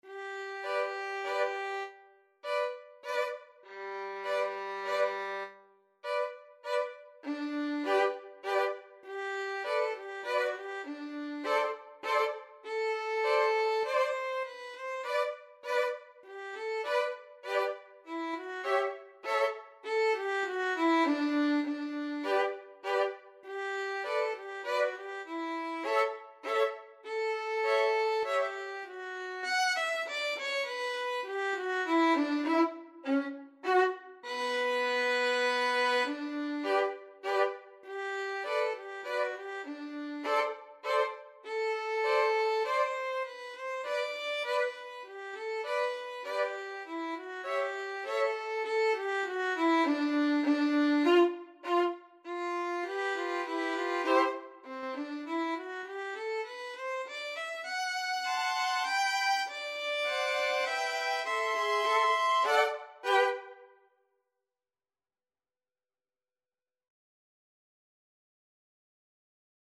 Violin 1Violin 2Violin 3
3/4 (View more 3/4 Music)
Slowly = c.100
Classical (View more Classical Violin Trio Music)